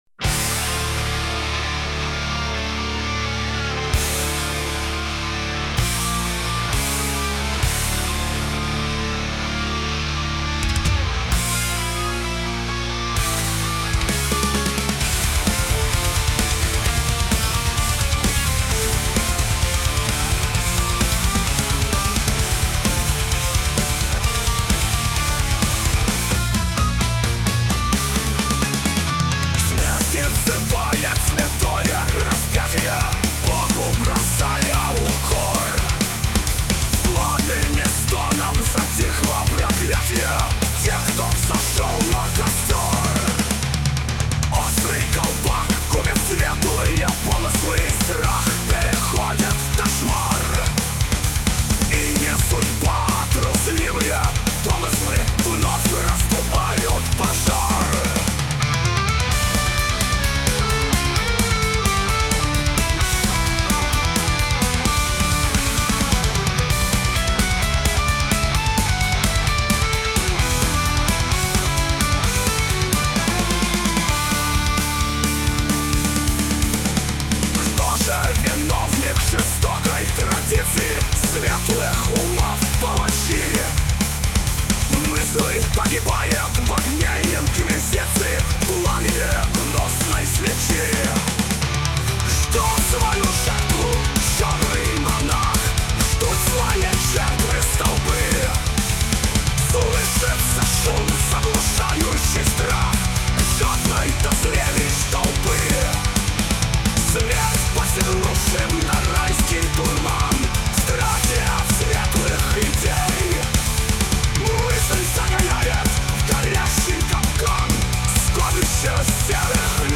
• Жанр: Металл